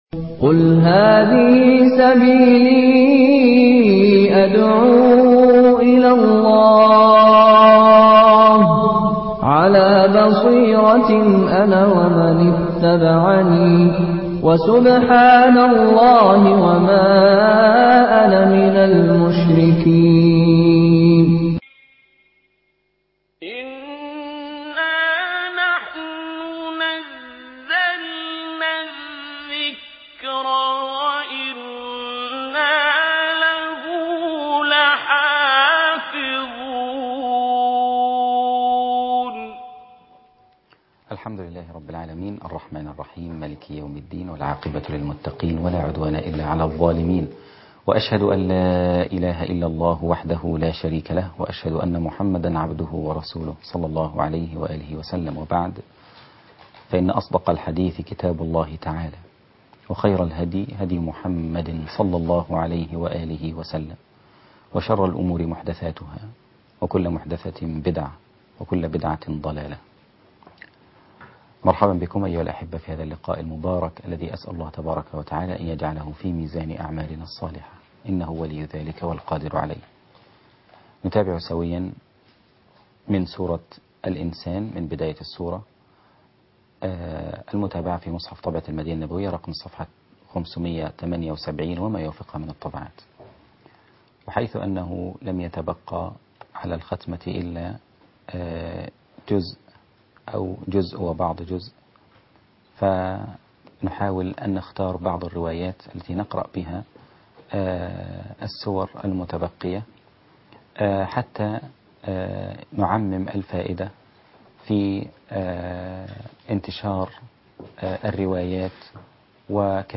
المقرأة